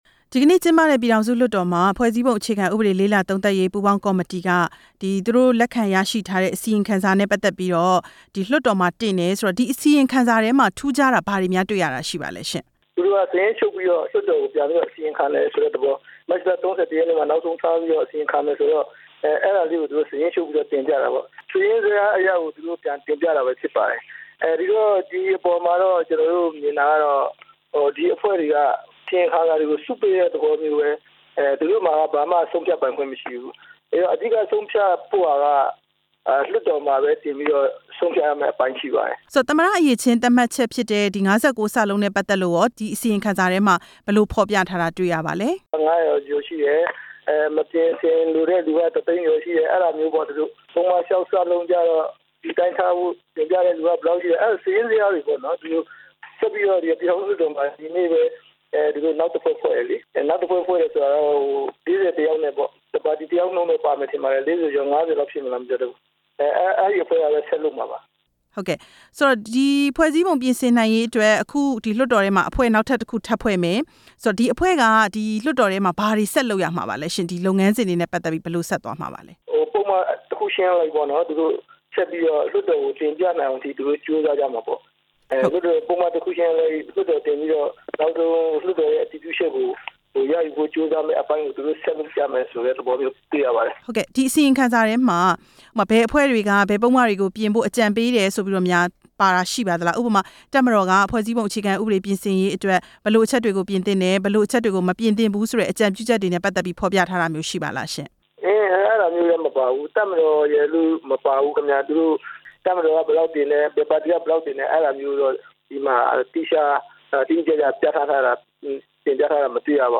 ပြည်သူ့လွှတ်တော်ကိုယ်စားလှယ် ဦးဖေသန်းနဲ့ မေးမြန်းချက်